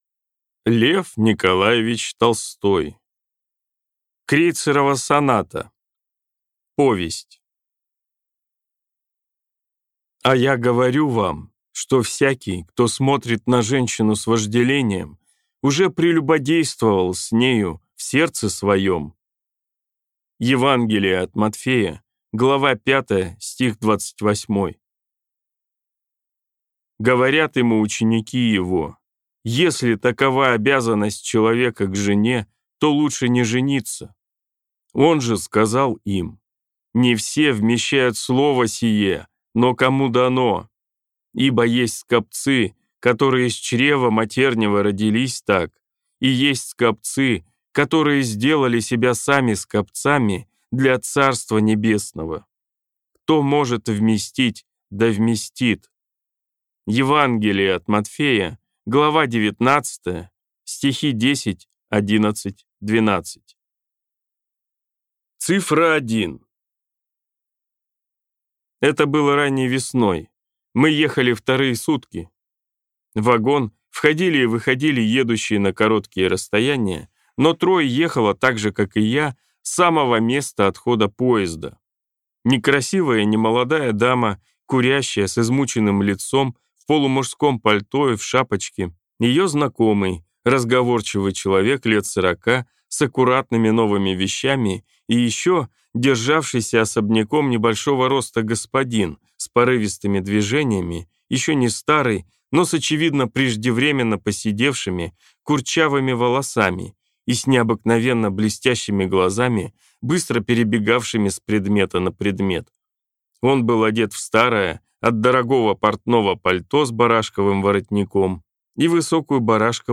Аудиокнига Крейцерова соната (сборник) | Библиотека аудиокниг
Прослушать и бесплатно скачать фрагмент аудиокниги